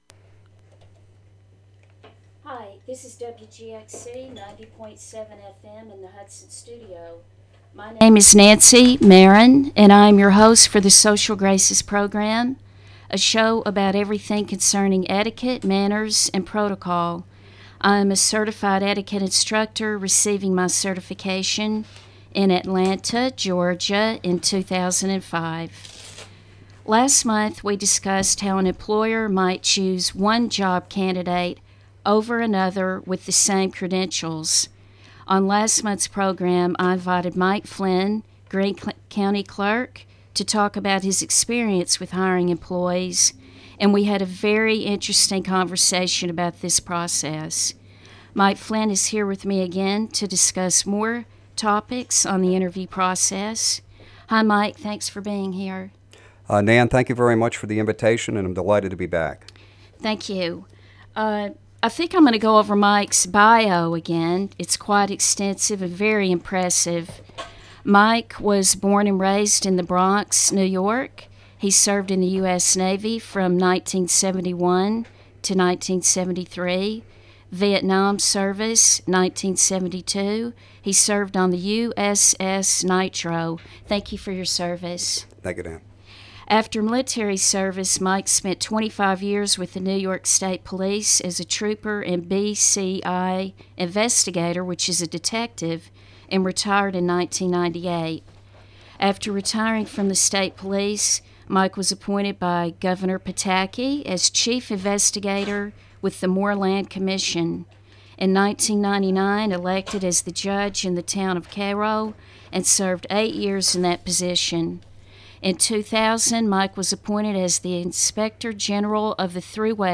Greene County Clerk Mike Flynn is a guest, and talks about applying for jobs.